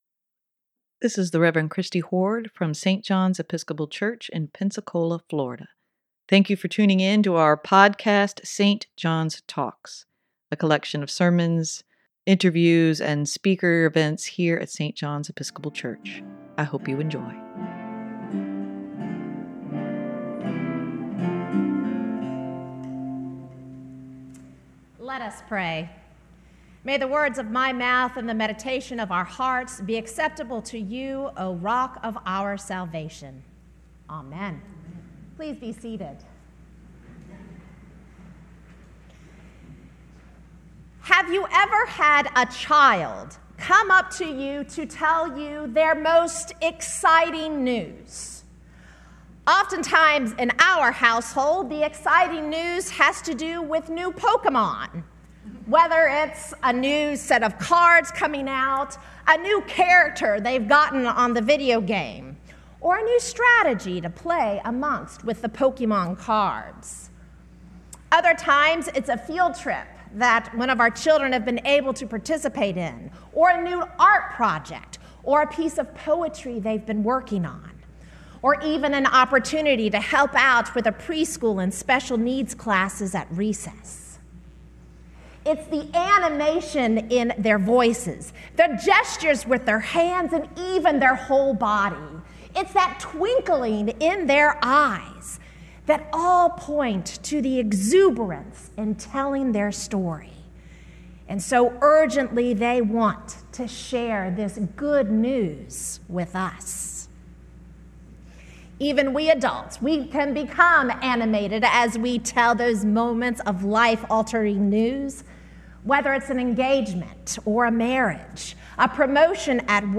Sermon for March 12, 2023: Finding God in the stream of living waters - St. John's Episcopal Church
sermon-3-12-23.mp3